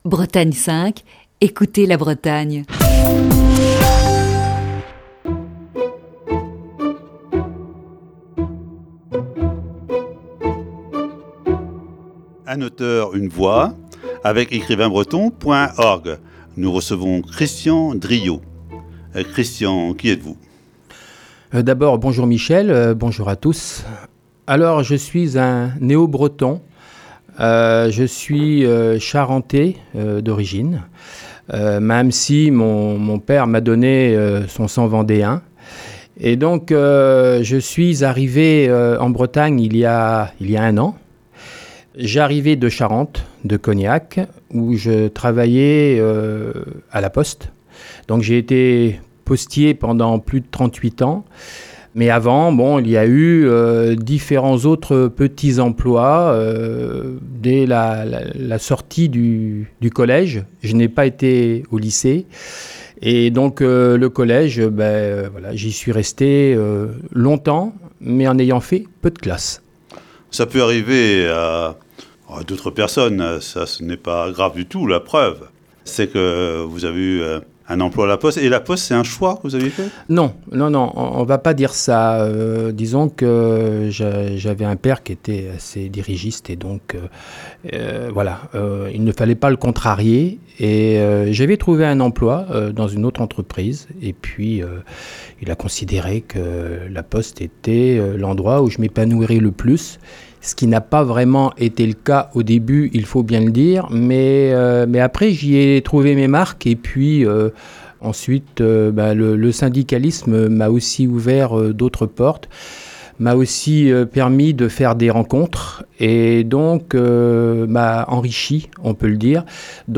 Voici ce lundi, la première partie de cet entretien.